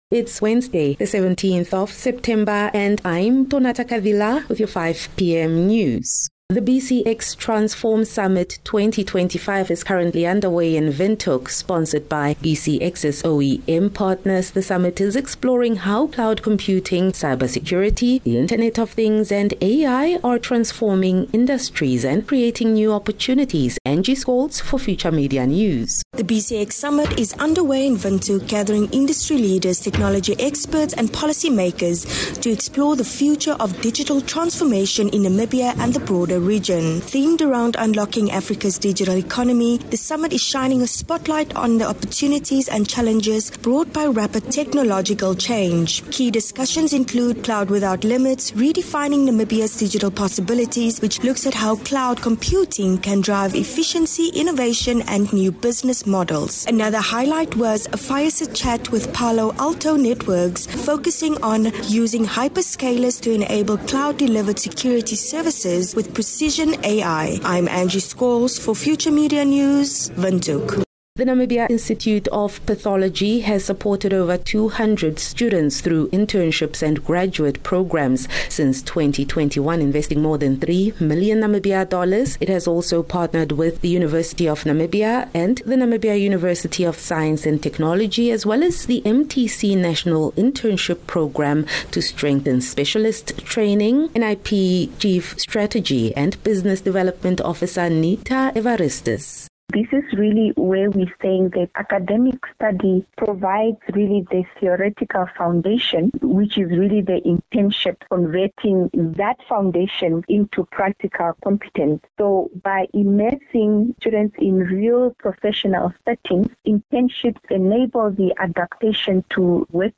17 Sep 17 September - 5 pm news